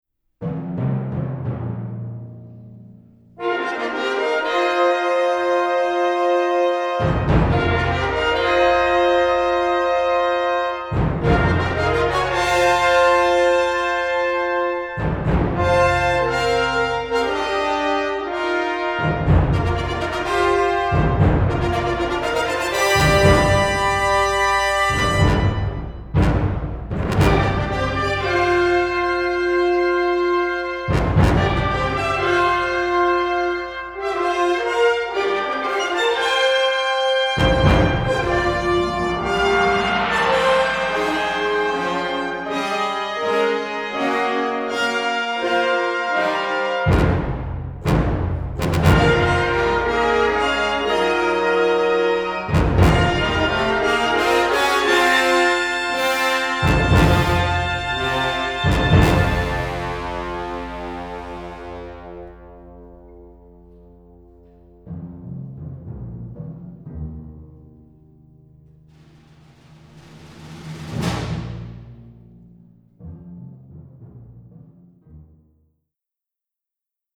crisp recording